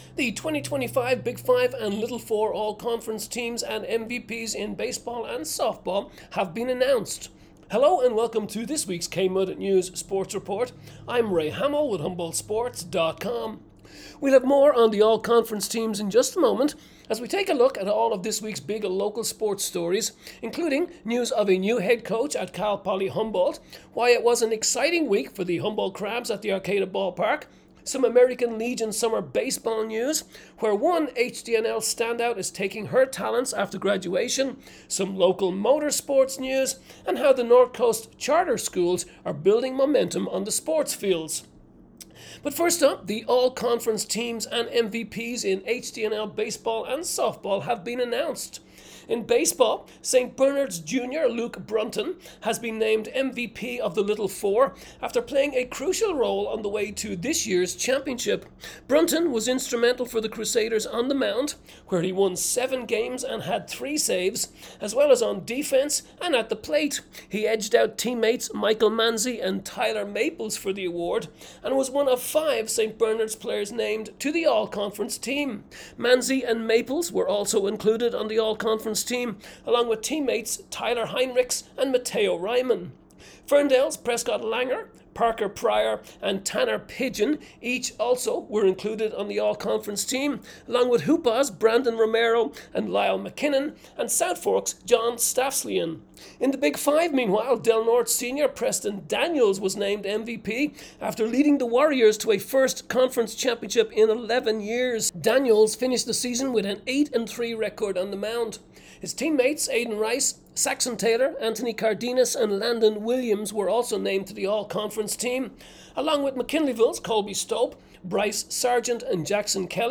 KMUD News Sports Report